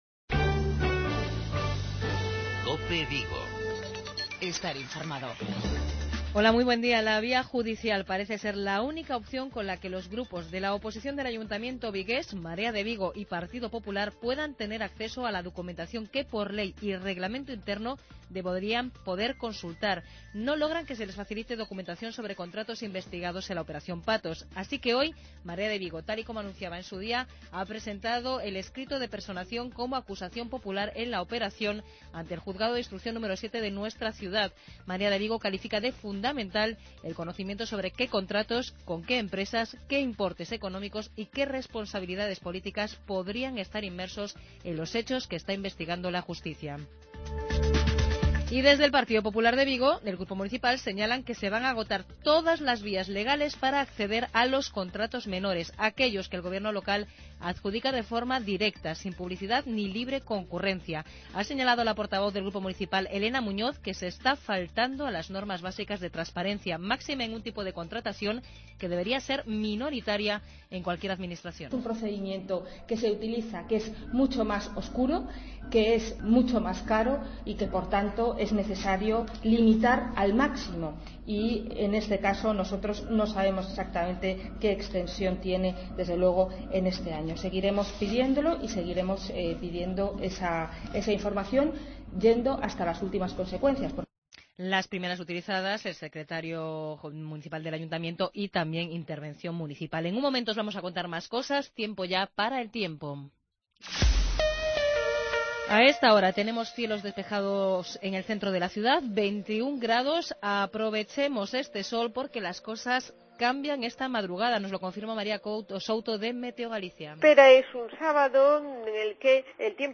Informativos Vigo